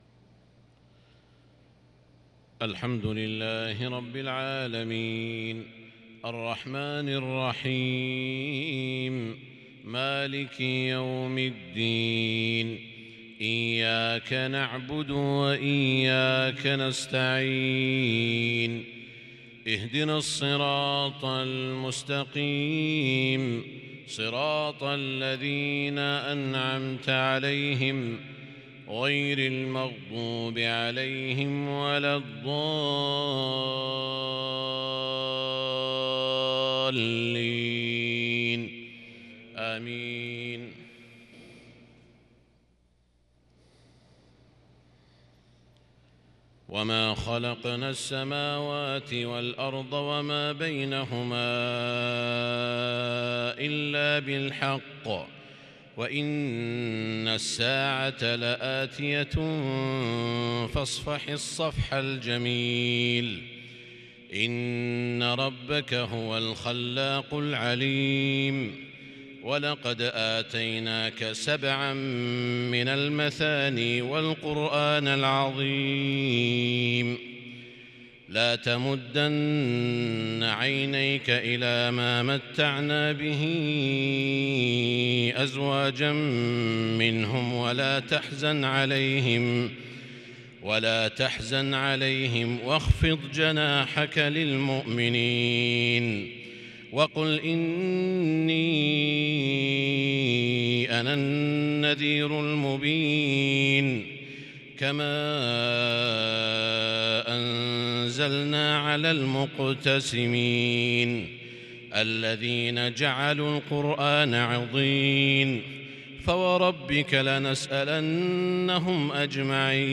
أواخر سورة الحجر | Isha prayer from surat Al-Hijr-|4-7-2021 > 1442 🕋 > الفروض - تلاوات الحرمين